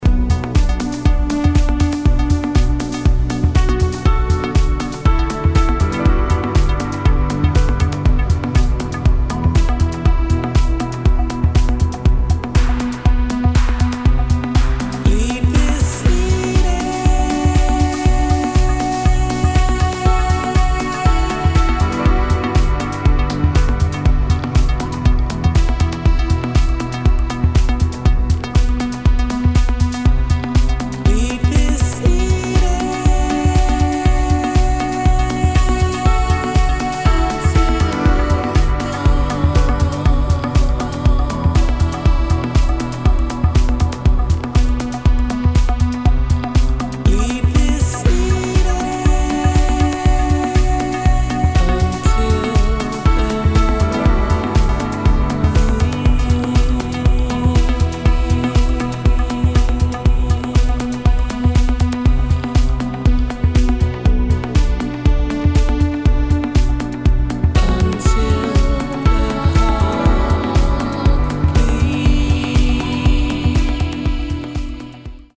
[ DEEP HOUSE | NU-DISCO ]